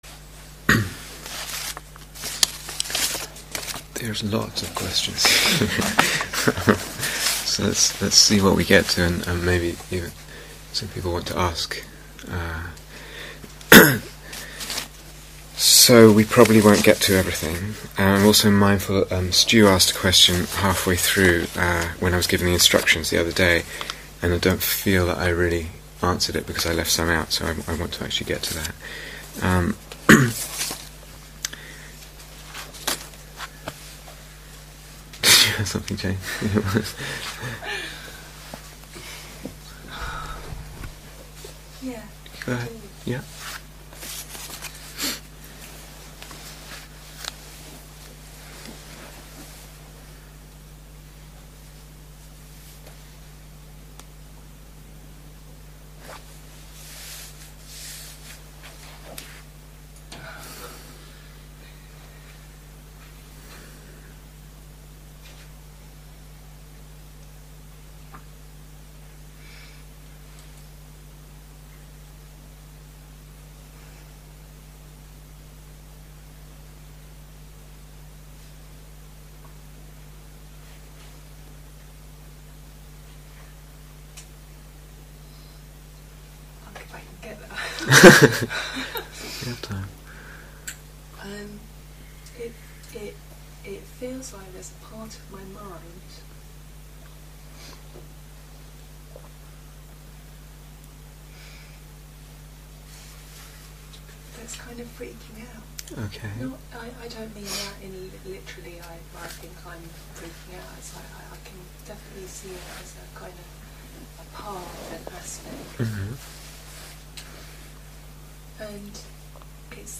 Questioning Reality (Question and Answer Session 5)